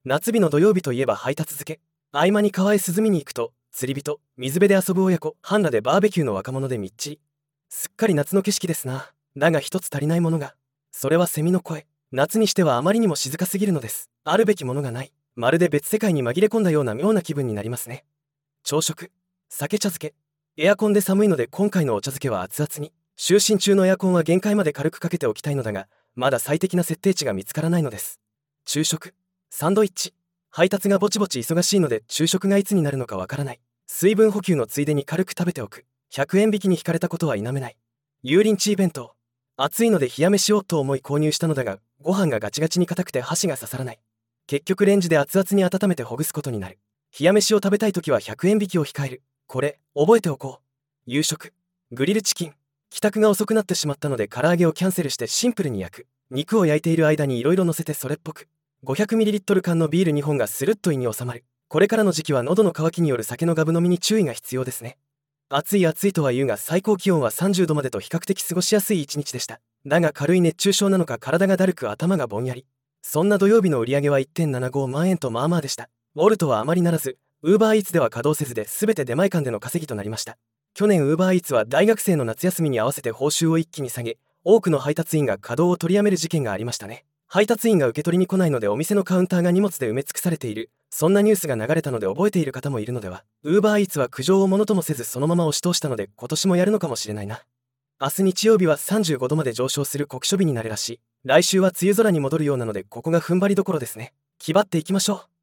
合間に川へ涼みに行くと、釣り人/水辺で遊ぶ親子/半裸でBBQの若者でミッチリ。
川の様子
それは蝉の声。
夏にしてはあまりにも静かすぎるのです。